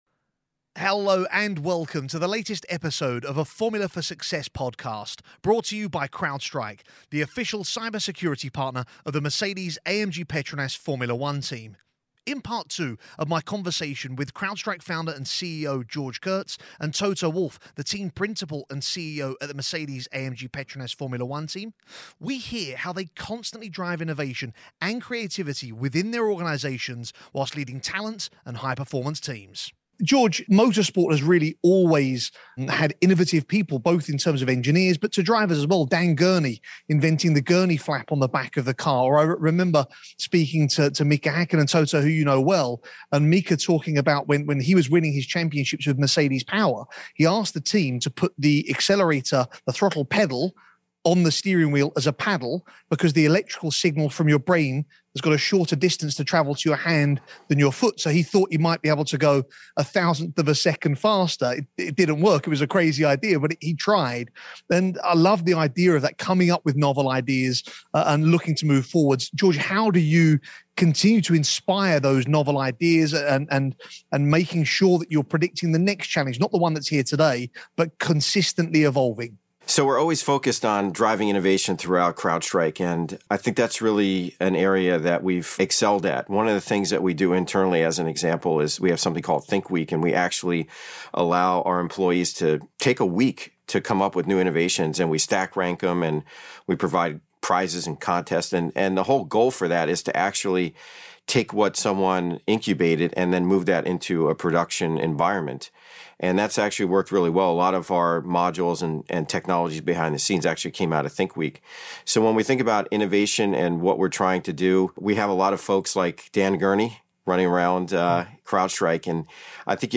CrowdStrike co-founder and CEO George Kurtz in conversation with CEO, Team Principal and co-owner of the Mercedes-AMG Petronas F1 Team, Toto Wolff – Part 2